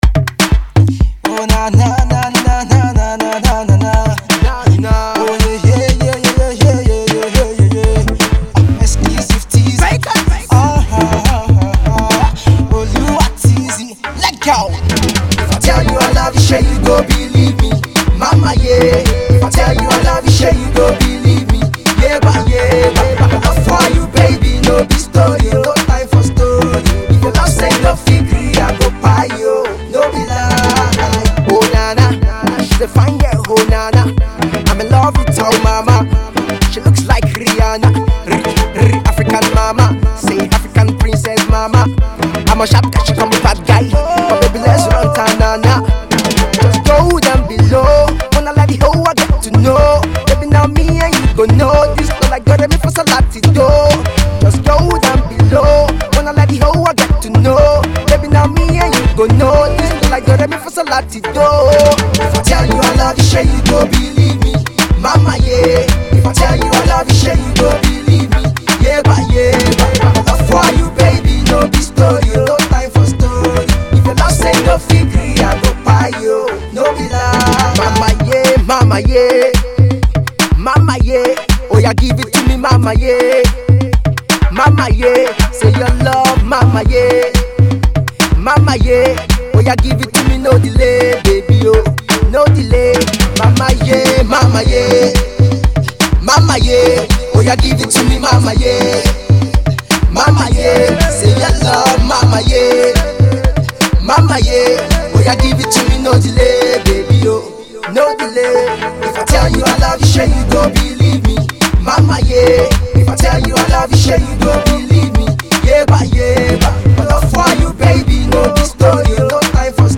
Fast rising singer
a lovely club banger